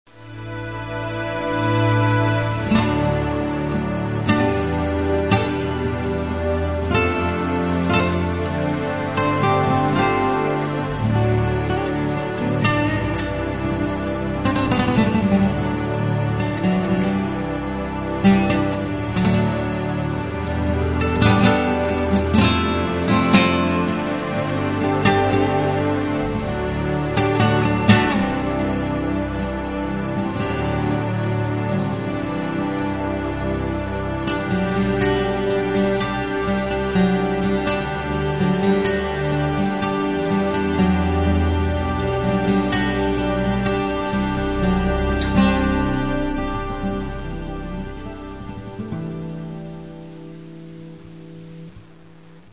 Каталог -> Джаз та навколо -> Збірки, Джеми & Live